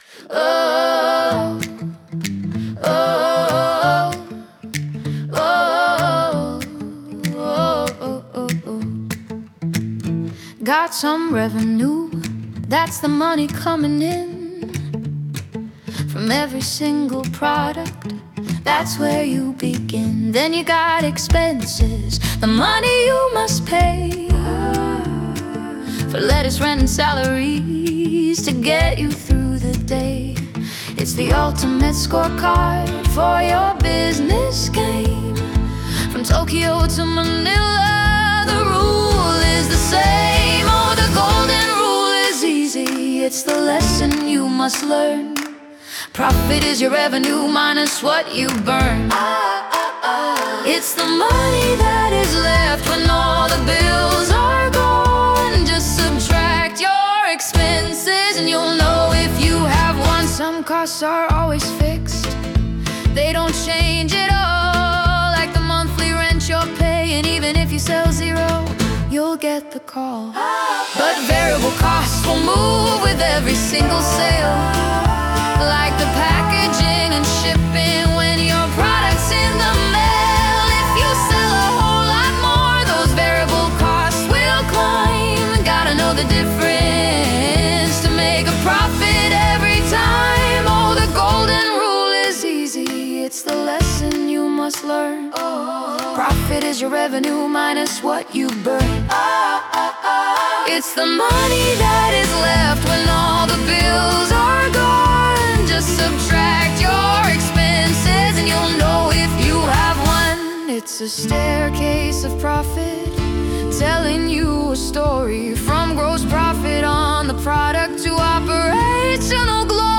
Curious About Our Karaoke Songs?